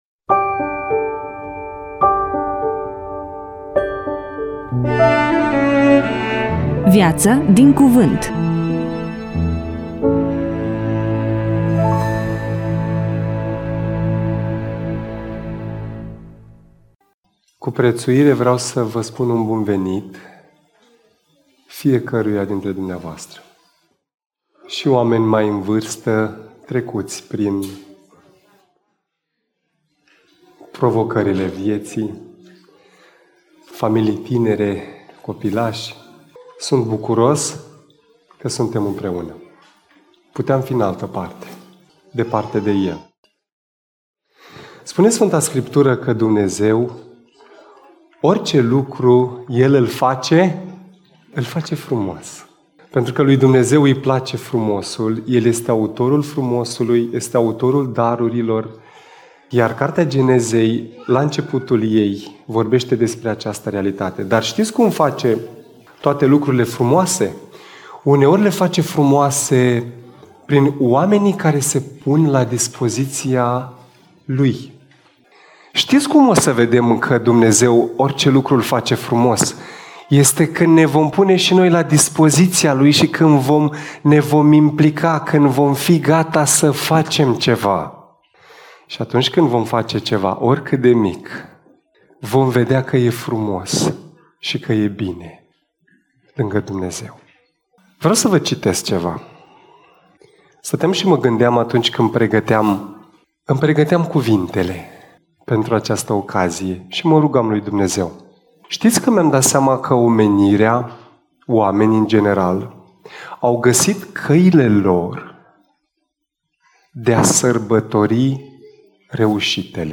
EMISIUNEA: Predică DATA INREGISTRARII: 30.01.2026 VIZUALIZARI: 20